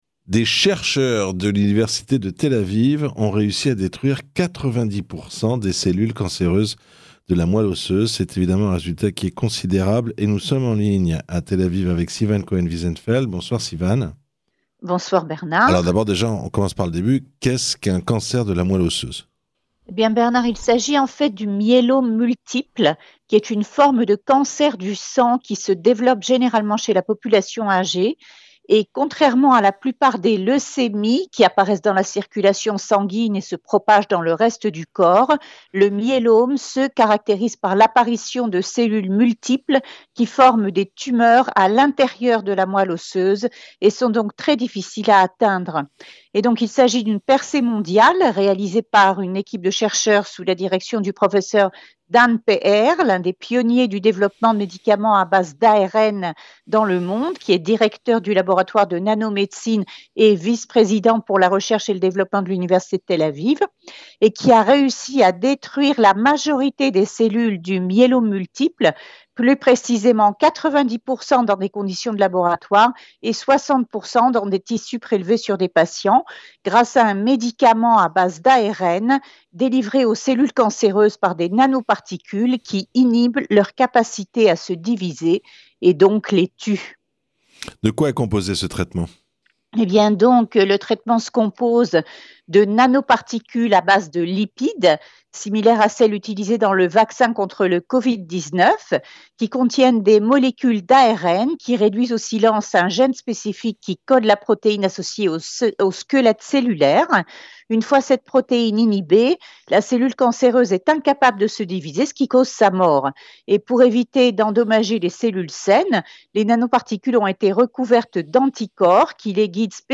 Les explications